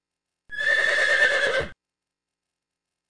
Pferdegeräusch Wiehern
Erstellt von: Pferd wiehert
Kategorie: Haustiergeräusche
Beschreibung: Hören Sie das beruhigende Wiehern eines Pferdes im Stall – ein echtes Klang-Erlebnis für Pferdefreunde.